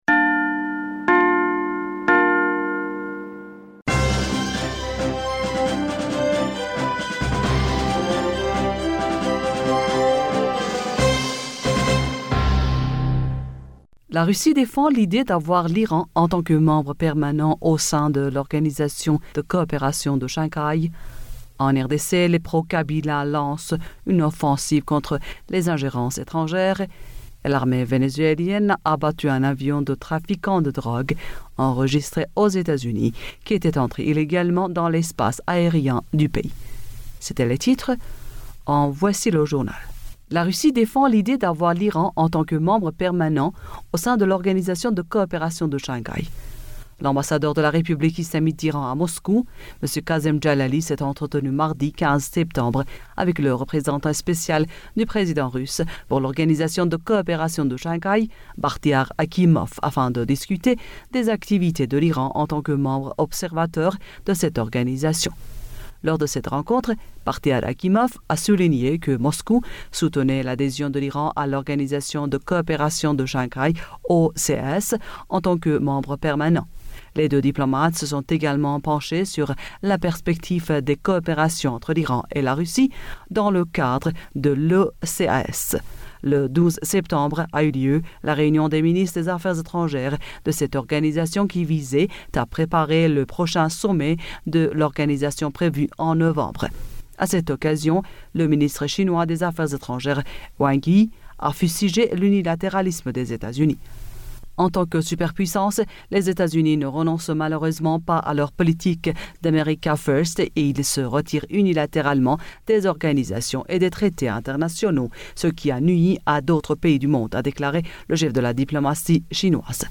Bulletin d'information du 17 septembre 2020